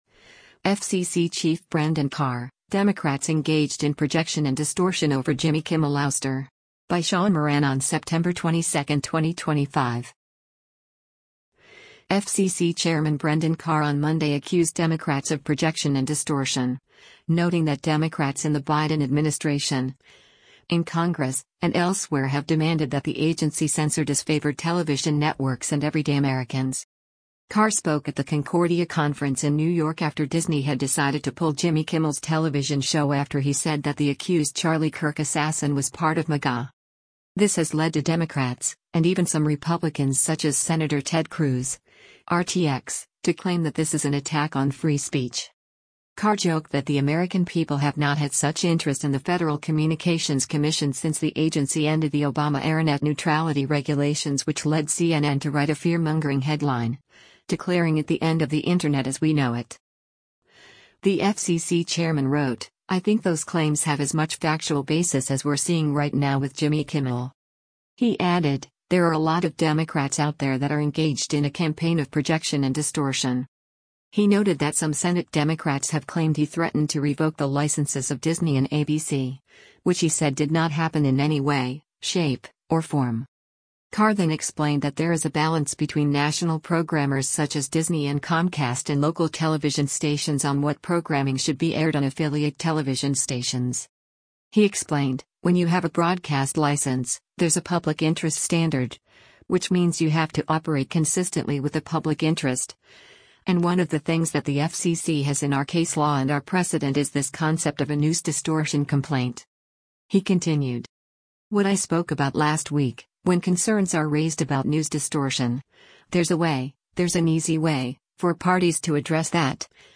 Carr spoke at the Concordia conference in New York after Disney had decided to pull Jimmy Kimmel’s television show after he said that the accused Charlie Kirk assassin was “part of MAGA.”